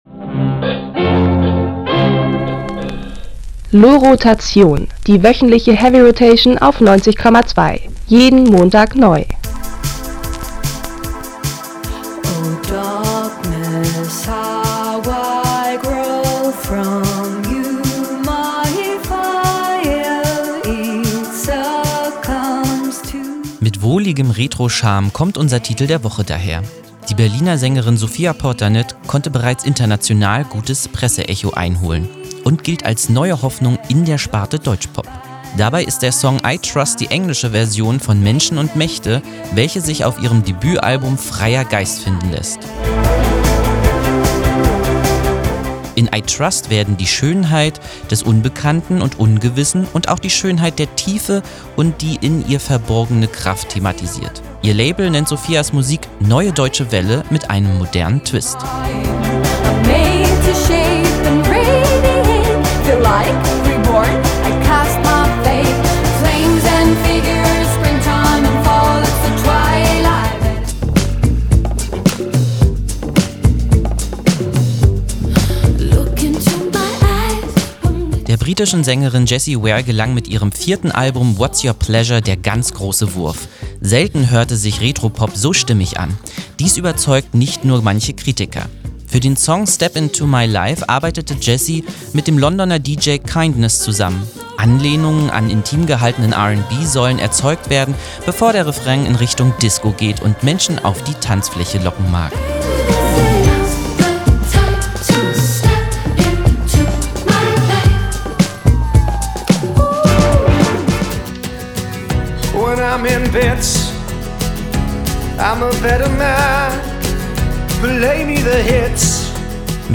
Spannender Genre-Mix einer Newcomerin